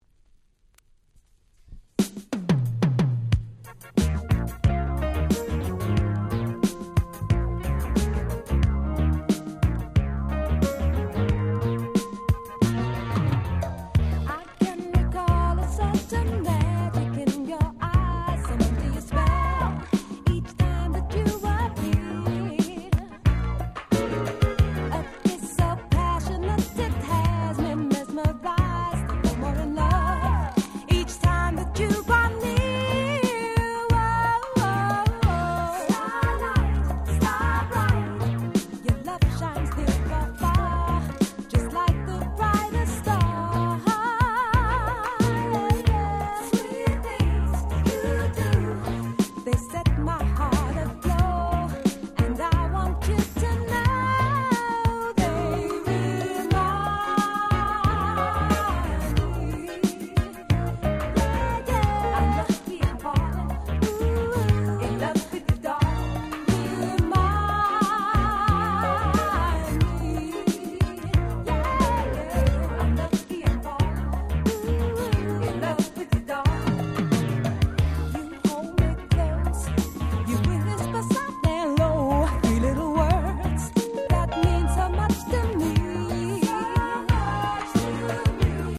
Very Rare Lovers Reggae !!